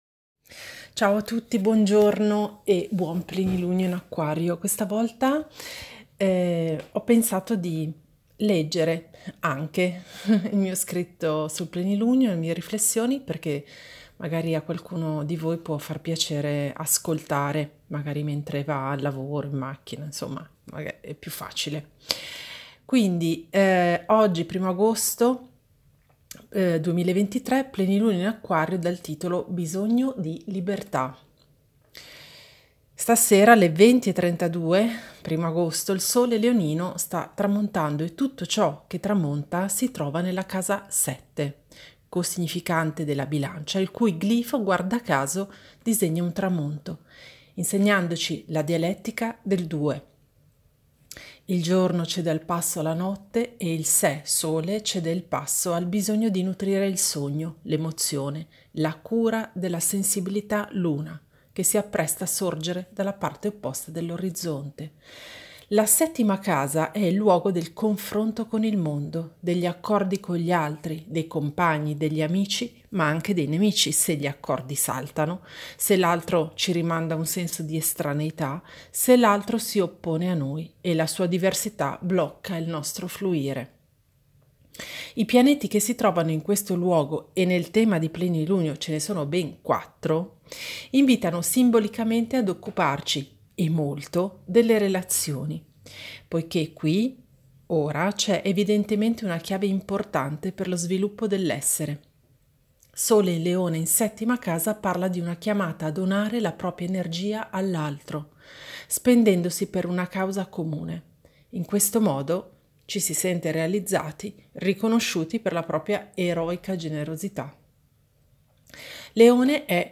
Nuovo! Ascolta la versione podcast, letta da me